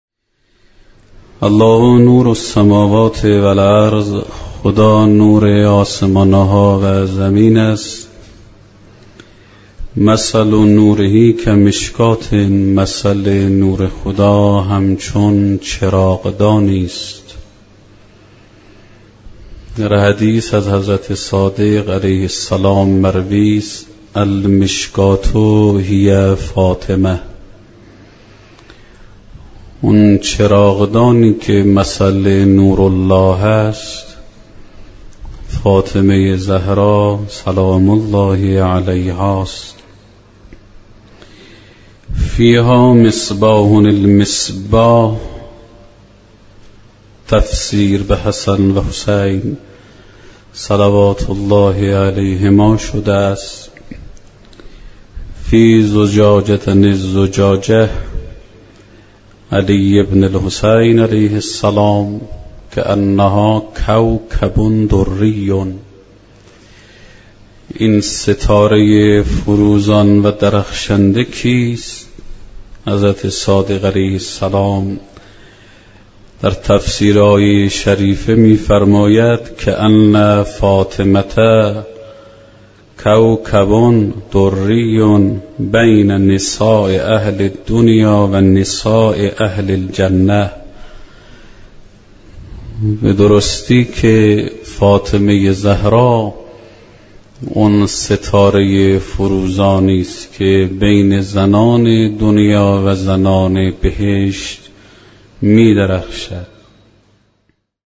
شهادت حضرت زهرا(س) آموزه‌ای متعالی در باب دفاع از آرمان‌های دینی و ارزش‌های اسلام است. ایکنا به مناسبت ایام سوگواری شهادت دخت گرامی آخرین پیام‌آور نور و رحمت، مجموعه‌ای از سخنرانی اساتید اخلاق کشور درباره شهادت ام ابیها(س) با عنوان «ذکر خیر ماه» منتشر می‌کند.